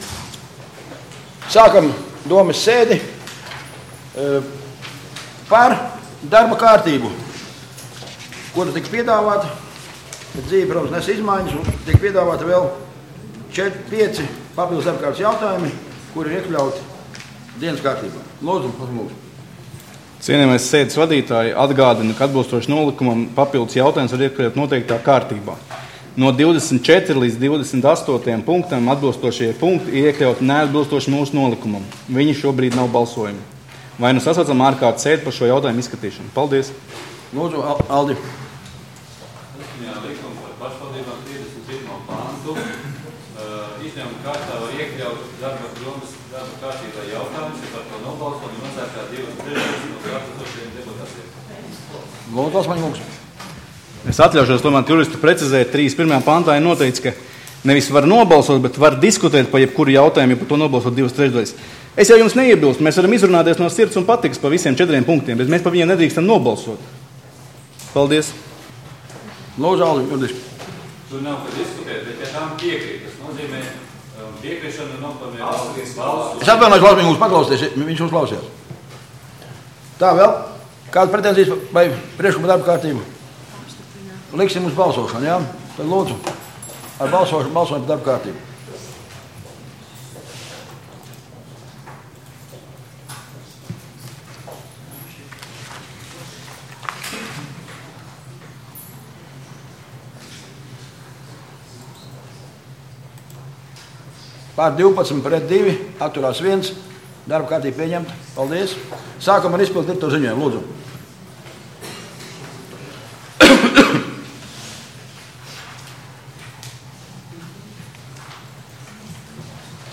Domes sēde Nr. 14